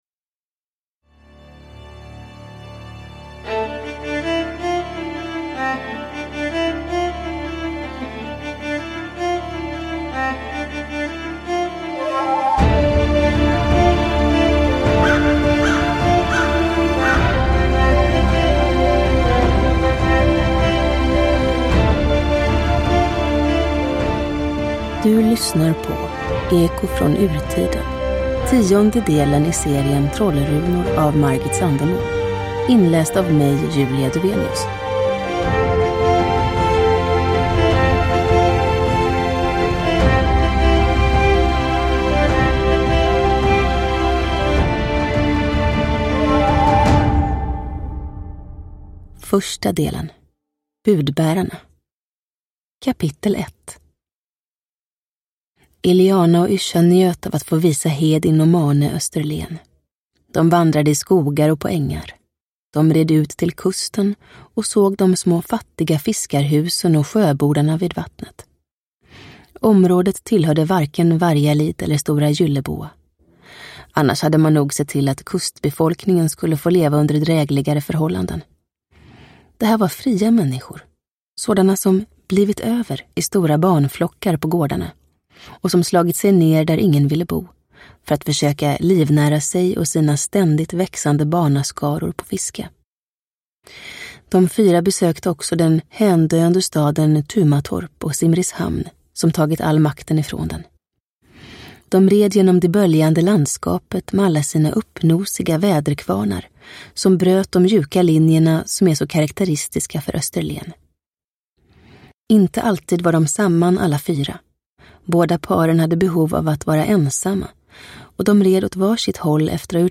Eko från Urtiden – Ljudbok – Laddas ner